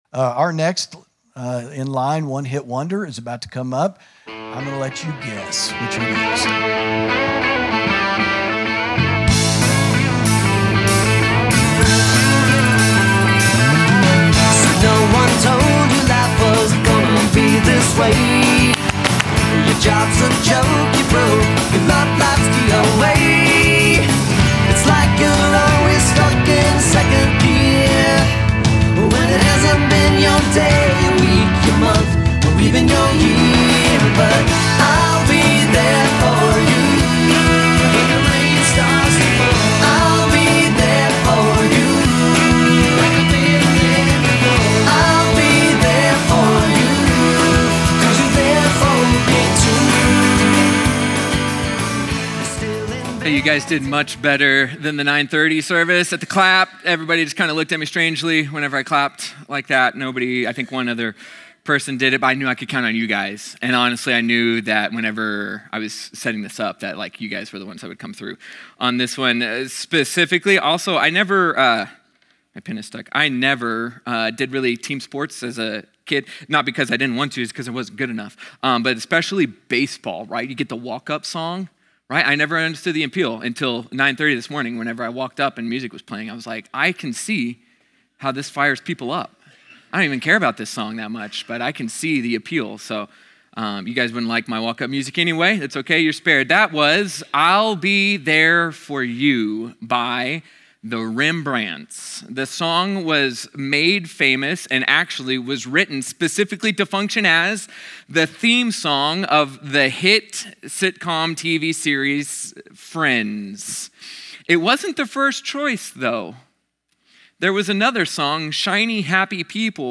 sermon audio 1019.mp3